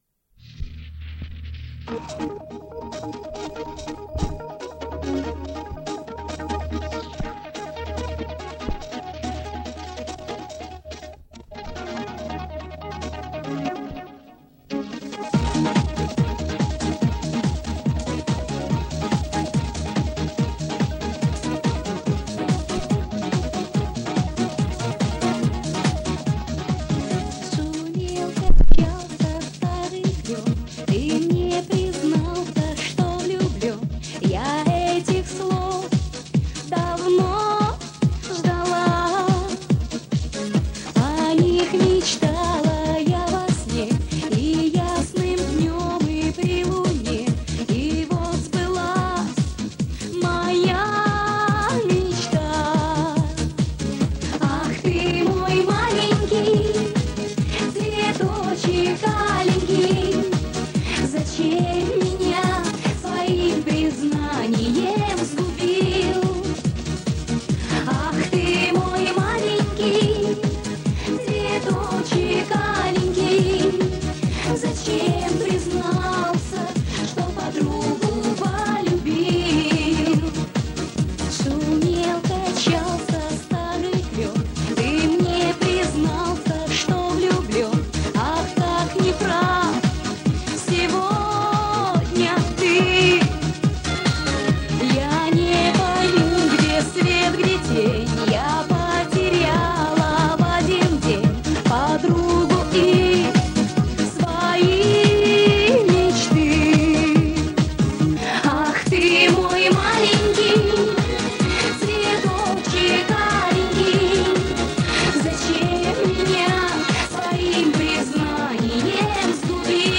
Плёнка посыпалась, качество ужасное, но узнаваемо. Подшаманил как мог.